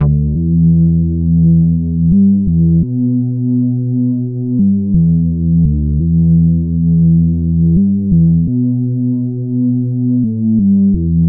低音合成器 Groovy 3
标签： 85 bpm Hip Hop Loops Bass Synth Loops 972.84 KB wav Key : E
声道单声道